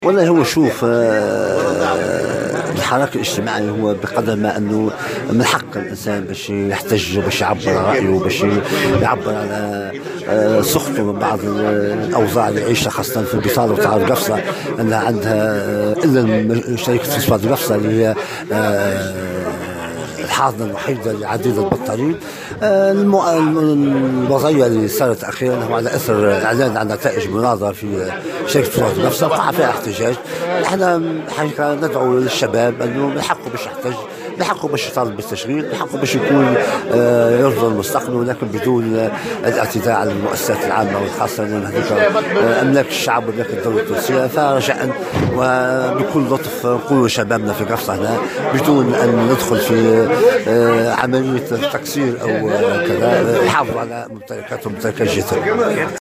على هامش أشغال المؤتمر الجهوي للشغل بقفصة